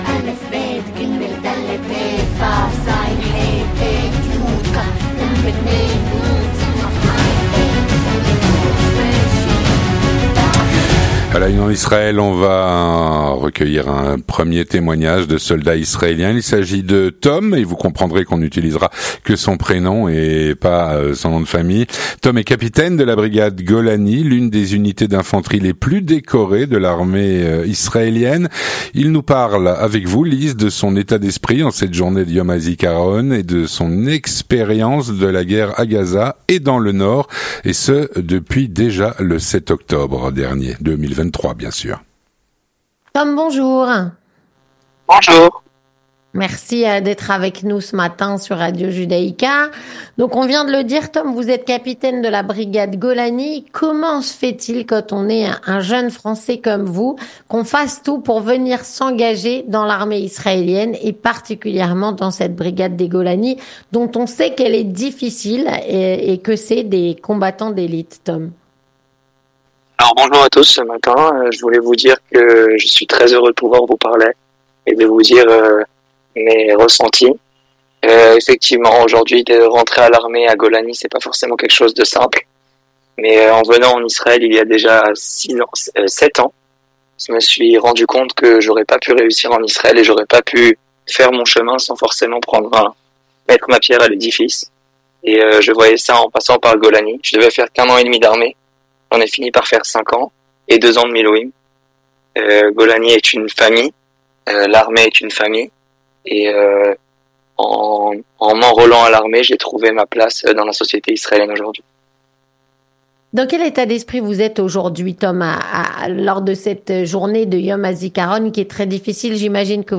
Il témoigne au micro